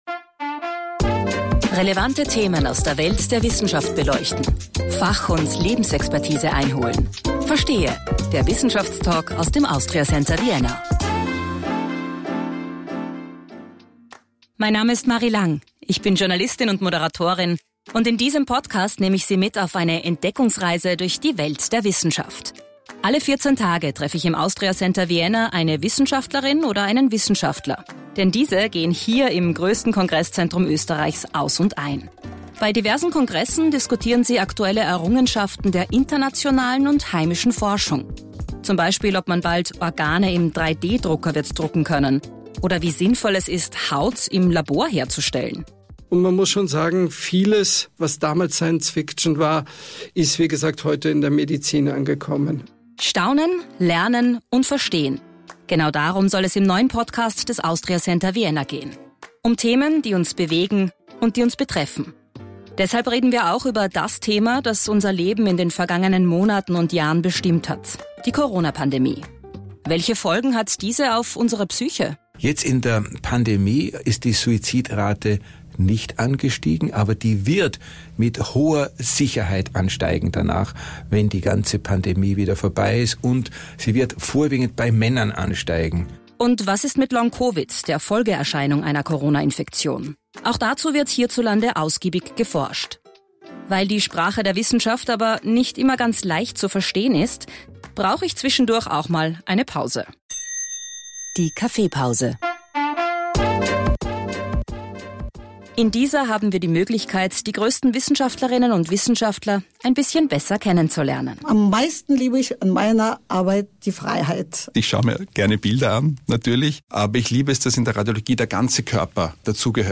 Verstehe! Der Wissenschaftstalk aus dem Austria Center Vienna